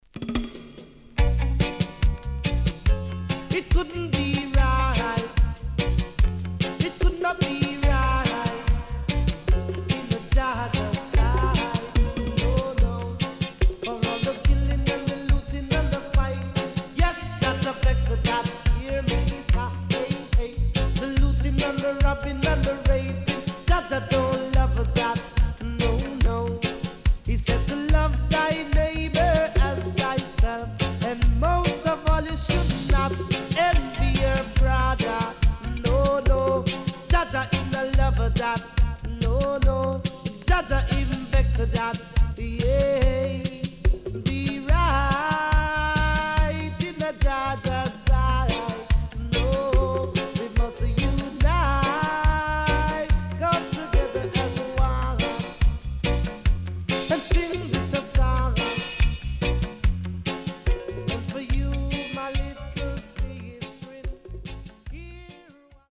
HOME > REISSUE USED [DANCEHALL]
SIDE A:少しノイズ入りますが良好です。